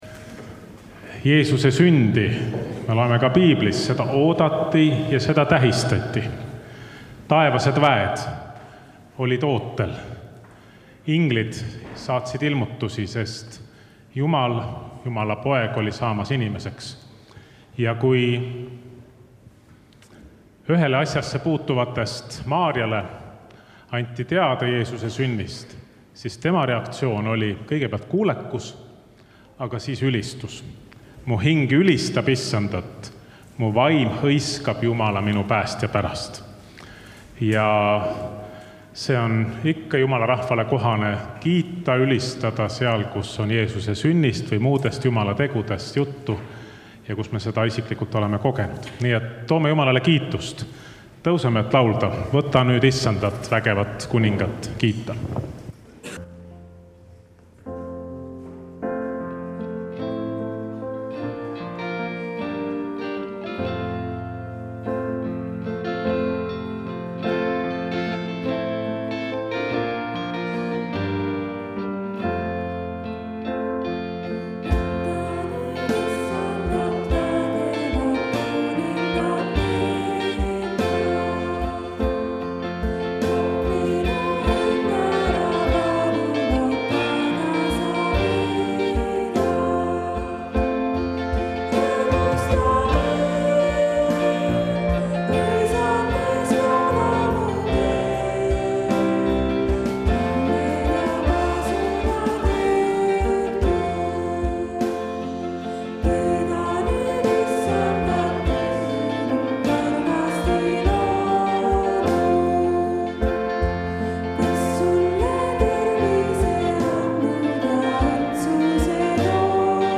Jutlus
Muusika: Kolgata noortebänd